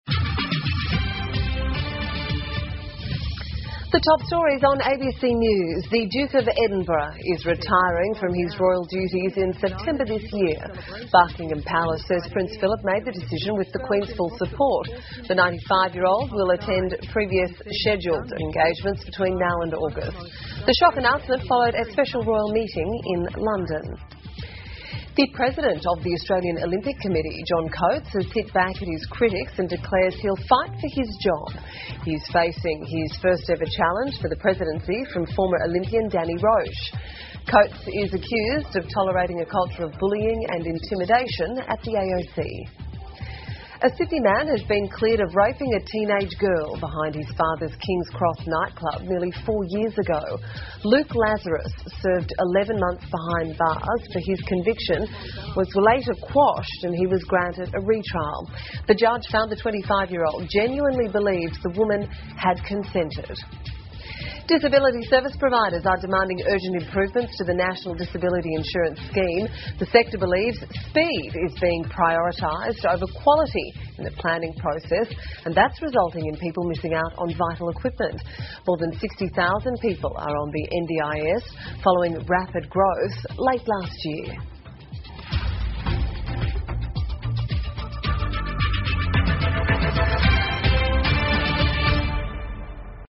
澳洲新闻 (ABC新闻快递) 英国菲利浦亲王将交卸王室职责 听力文件下载—在线英语听力室